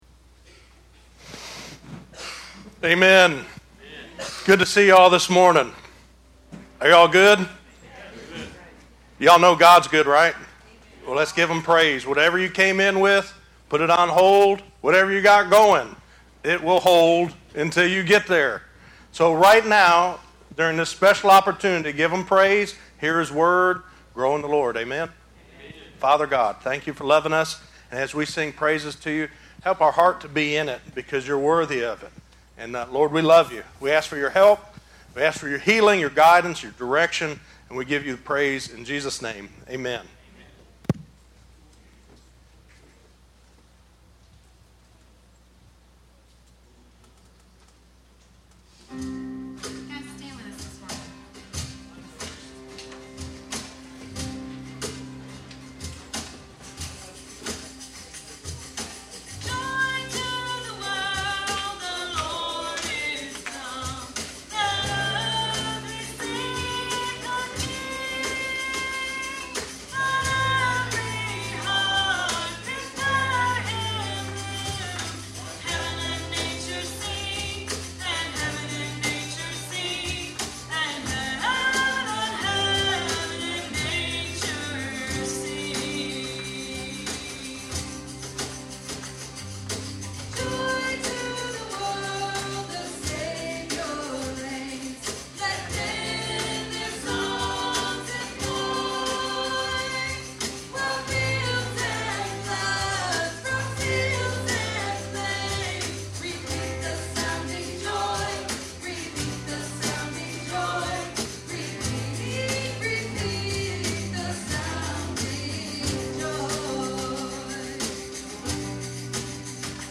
The Gospel of Jesus Christ the Son of God 8 - Messages from Christ Community Church.